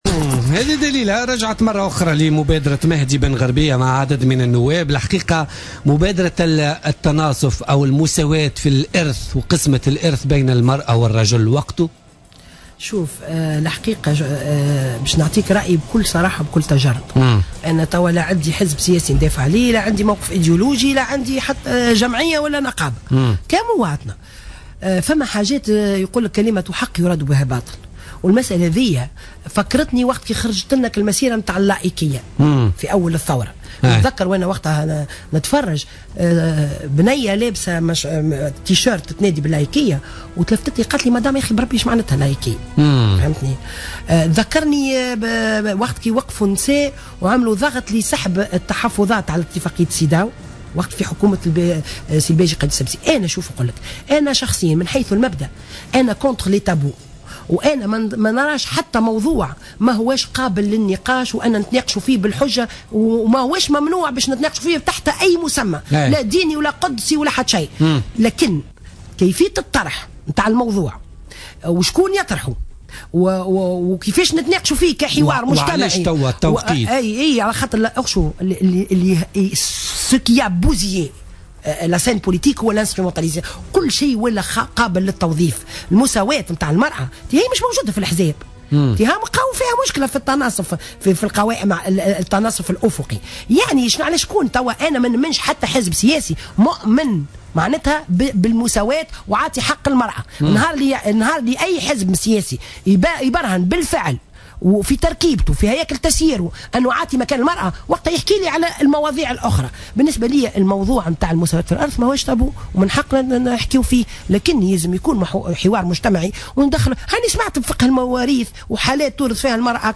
ضيفة برنامج بوليتيكا